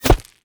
bullet_impact_dirt_07.wav